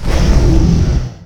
combat / creatures / dragon
attack3.ogg